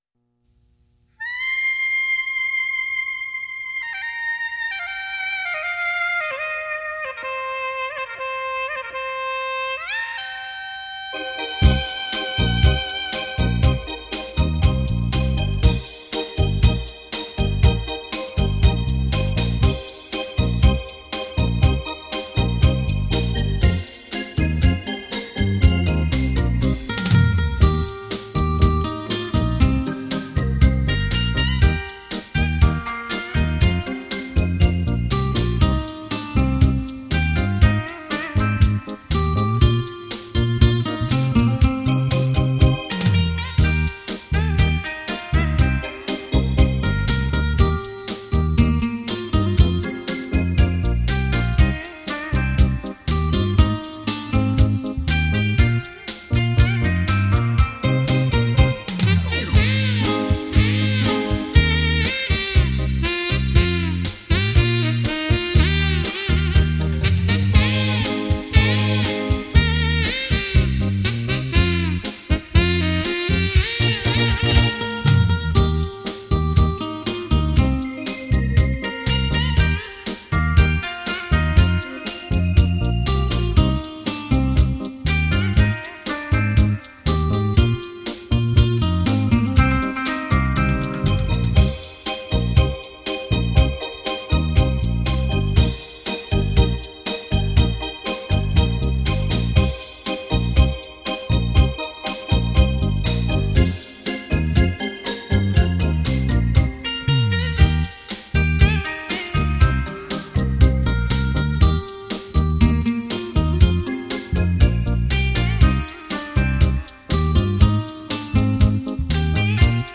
懷念動人的音樂演奏
悠揚迴盪